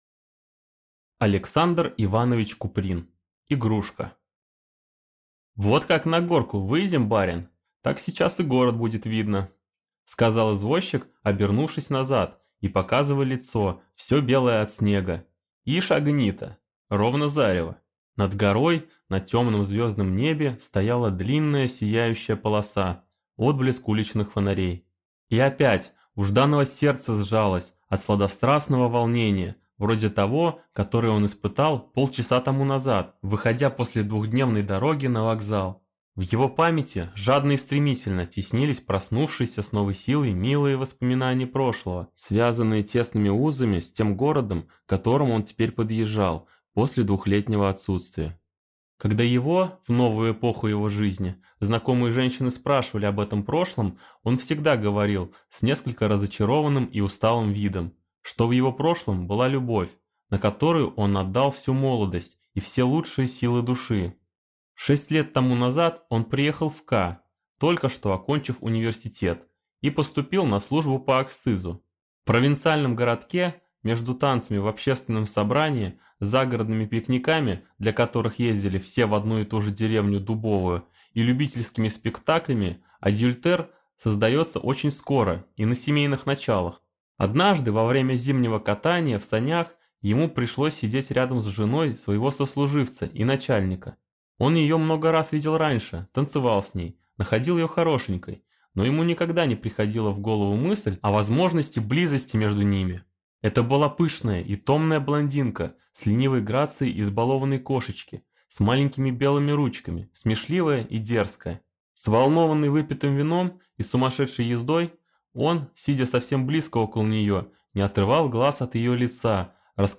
Аудиокнига Игрушка | Библиотека аудиокниг